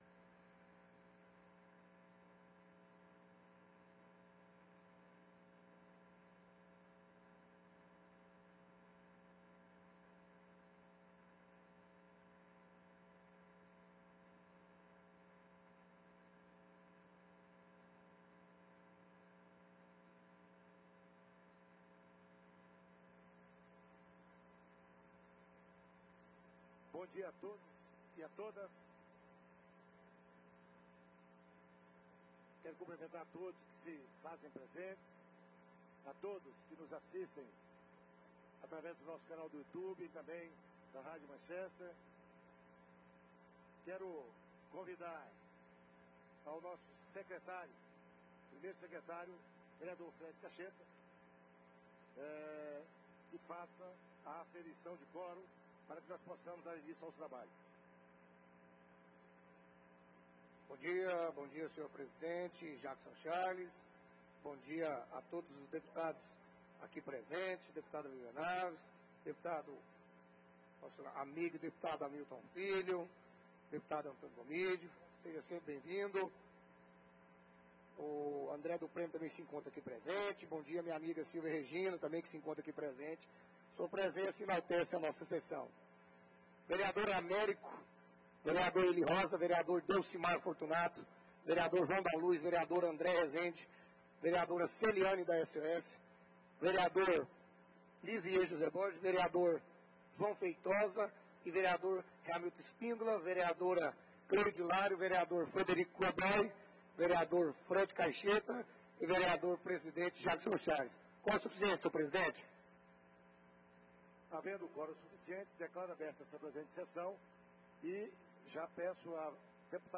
80ª Ordinária da 3ª Sessão Legislativa da 19ª Legislatura 21/11/23 Terça Feira.